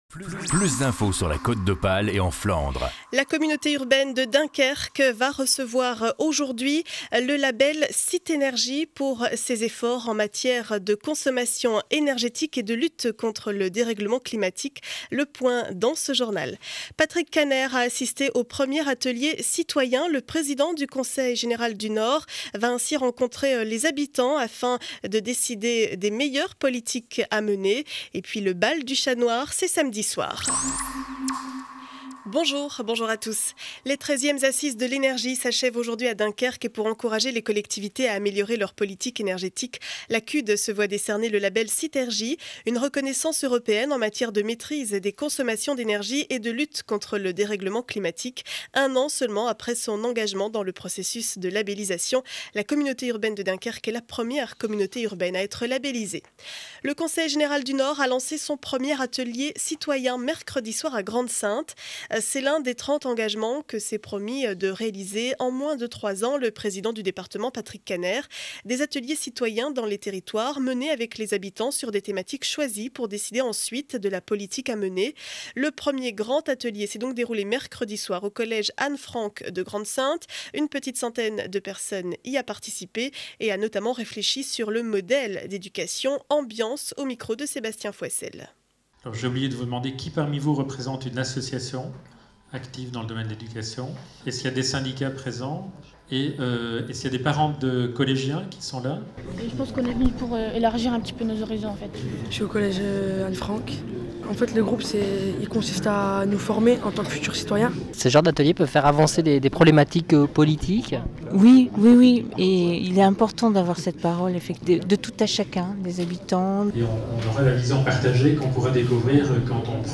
Journal du vendredi 27 janvier 2012 7 heures 30 édition du Dunkerquois.